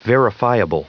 Prononciation du mot verifiable en anglais (fichier audio)
Prononciation du mot : verifiable